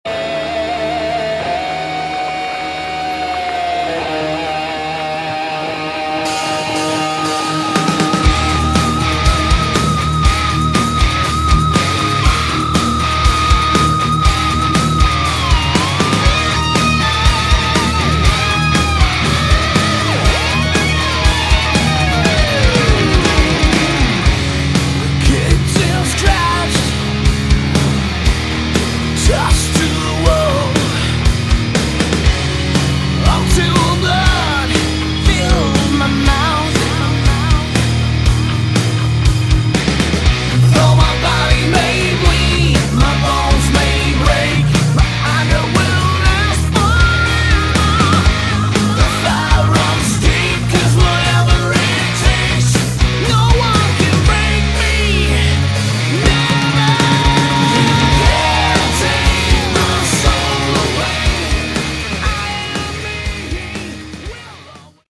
Category: Hard Rock
guitars, bass, keyboards
drums